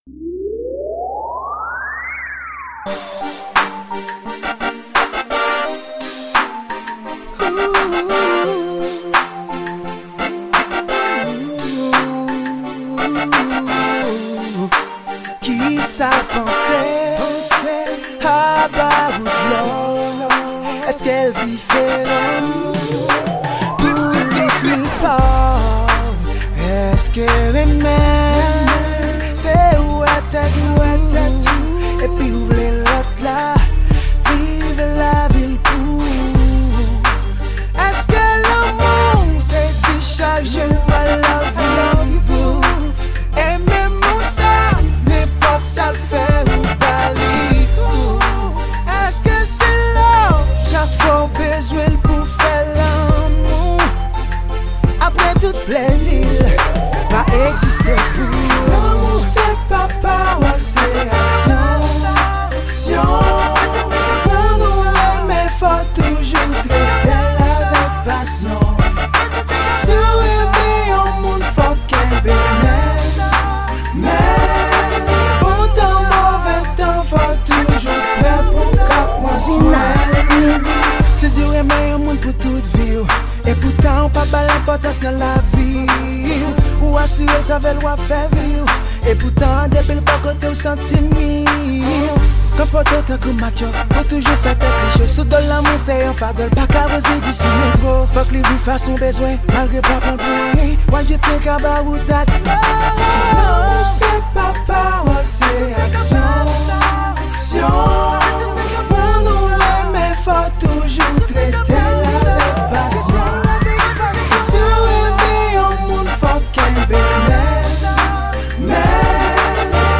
Genre: RNB.